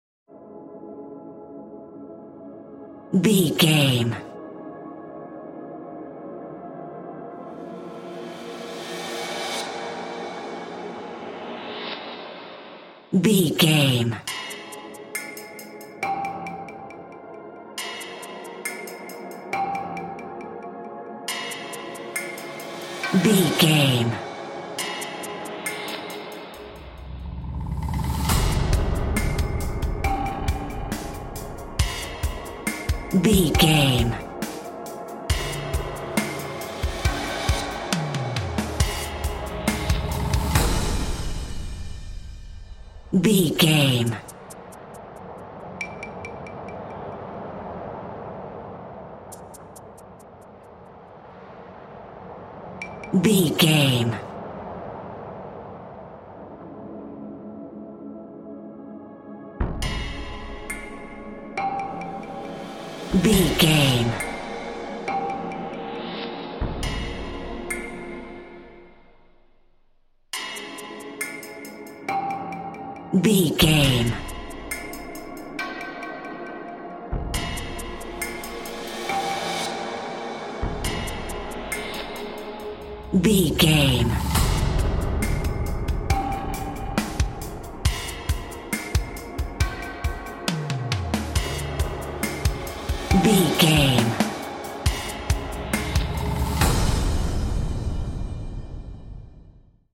Aeolian/Minor
B♭
eerie
ominous
medium tempo
drum machine